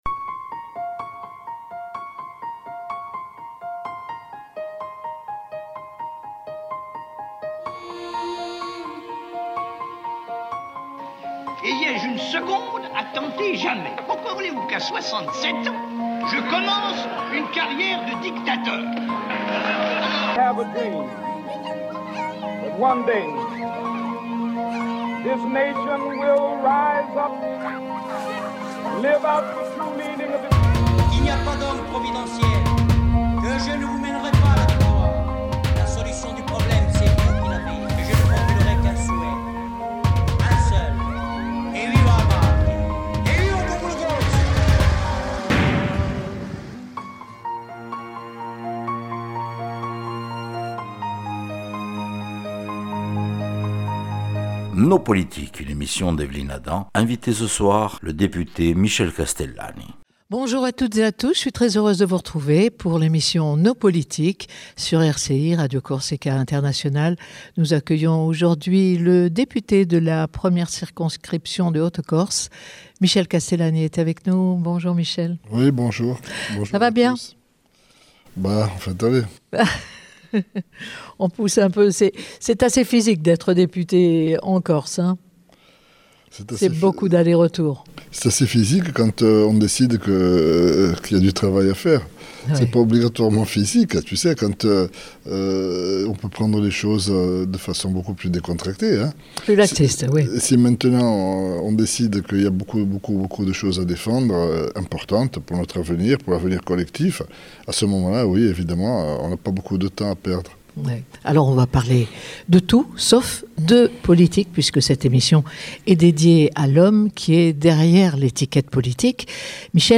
NO POLITIC- INVITE LE DEPUTE MICHEL CASTELLANI